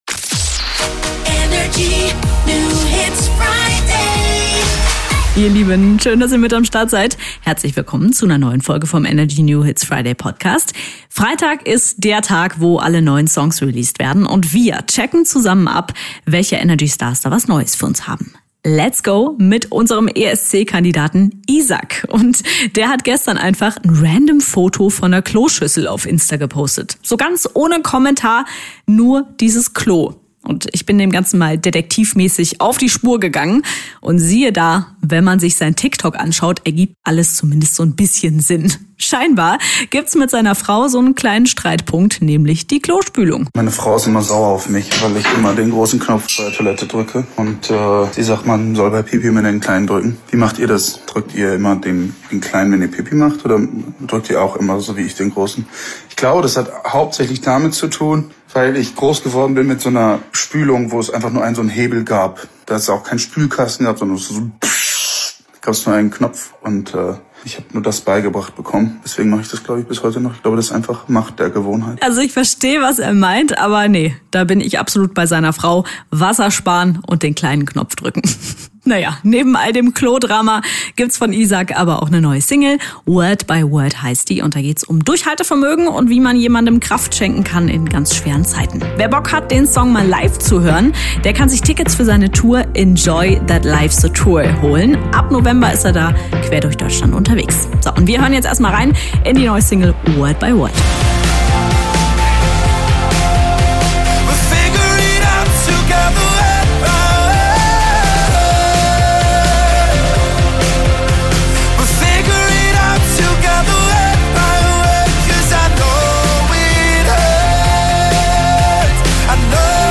stellt brandneue hitverdächtige Songs vor.
Musik